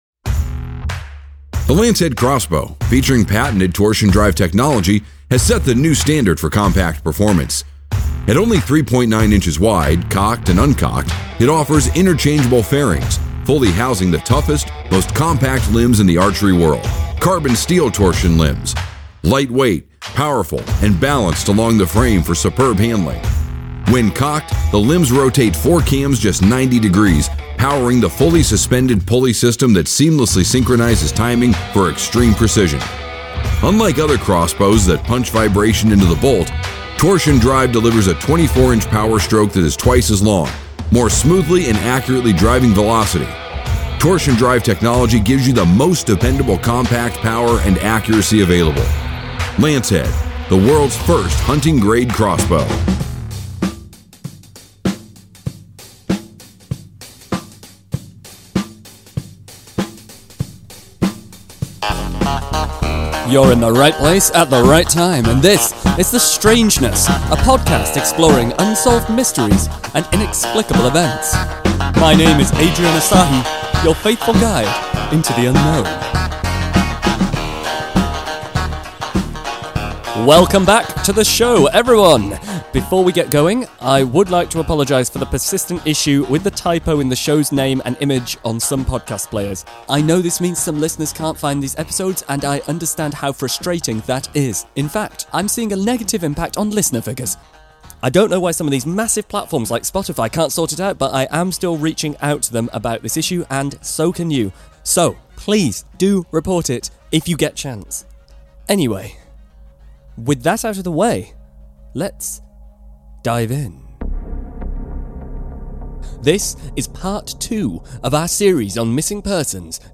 Join me in this episode, as I investigate a lead, interview witnesses and experts, then stumble onto the trail of a coverup – one that seems to conceal an even more sinister truth.